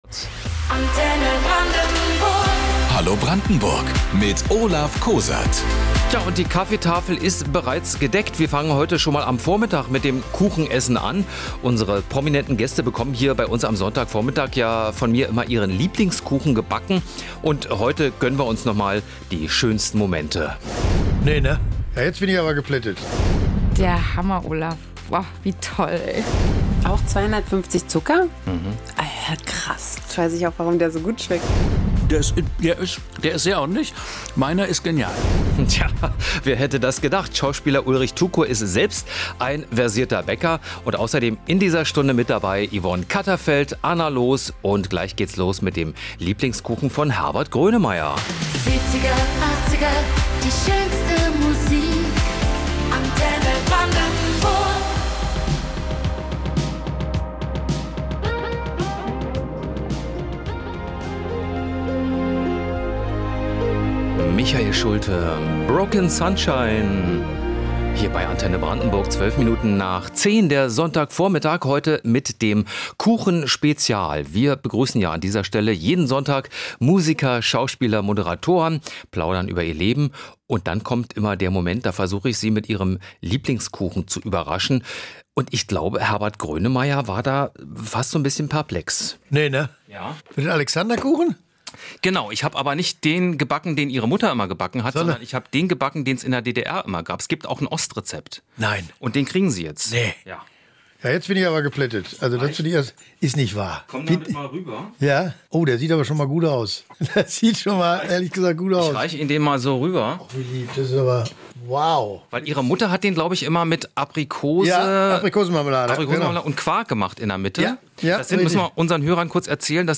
Am Sonntag gab es ein „Best of“ der schönsten Back-Gespräche, die Sie hier nachhören können.